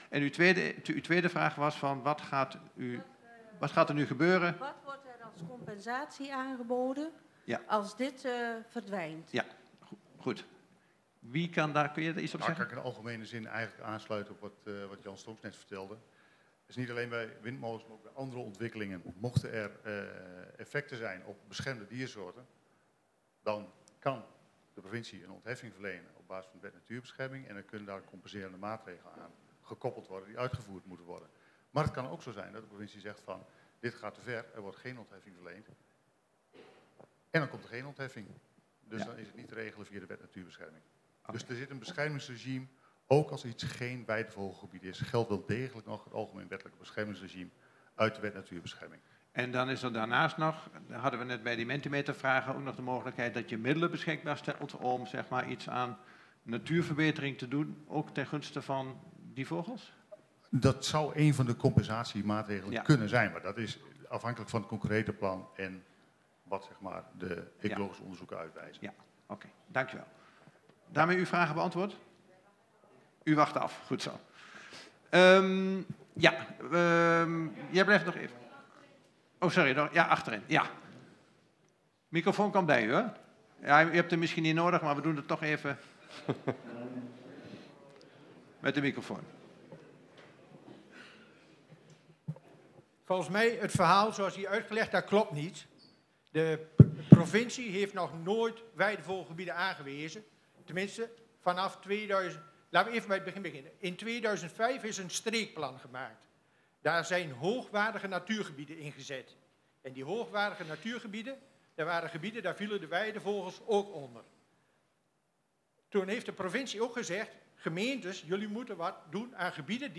Voor een exacte weergave van hetgeen is gezegd, zijn geluidsopnamen van de avond beschikbaar.
Ruim bezochte themasessie Ruim 90 inwoners waren aanwezig bij de themasessie over windturbines en natuur & landschap. De avond start met plenaire presentaties om het thema toe te lichten.
Tijdens het plenaire deel is ruimte voor enkele vragen uit de zaal.